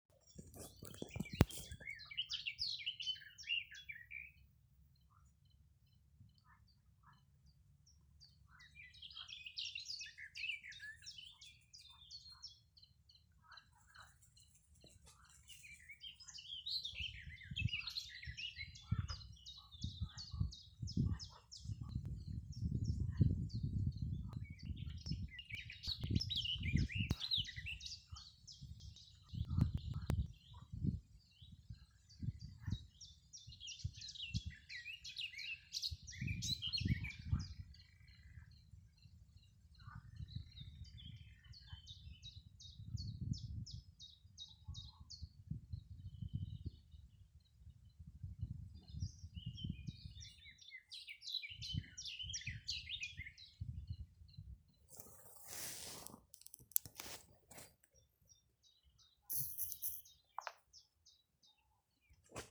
Blackcap, Sylvia atricapilla
StatusSinging male in breeding season
Notesdzied vītolu/ kārklu krūmājā